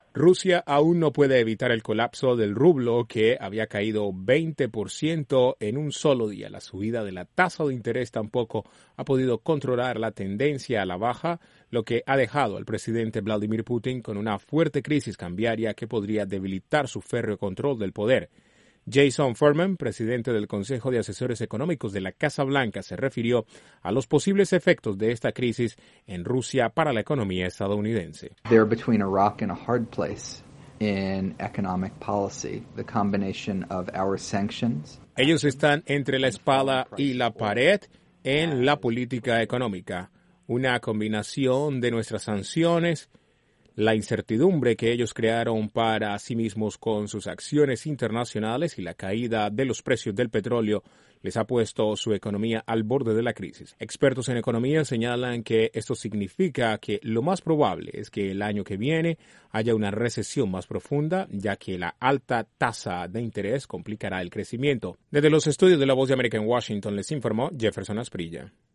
Estados Unidos viene monitoreando la problemática económica de Rusia y la Casa Blanca anunció que el presidente Obama tiene la intención de firmar un nuevo paquete de sanciones por el rol de Rusia en la crisis de Ucrania. Desde la Voz de América en Washington informa